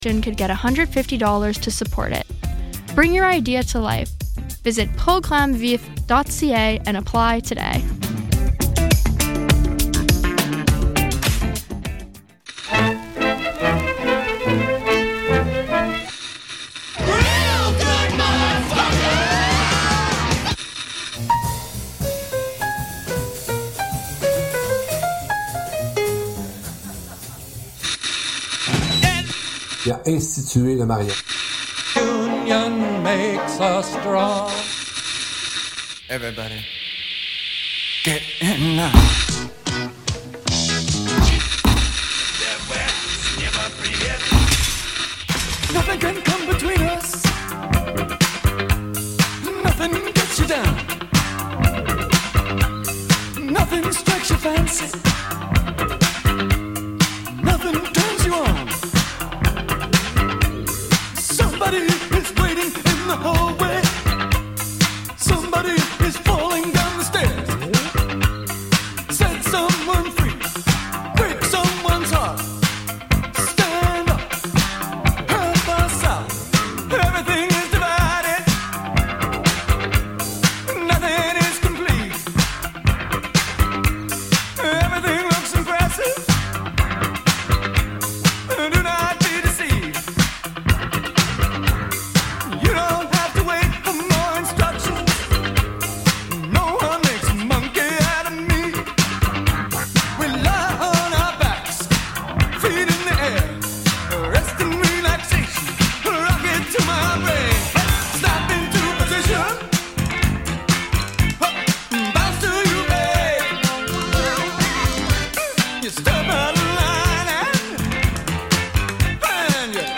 Bright Side of the Poo attempts this in a novel way, at least with regard to Bozonian practice, through music.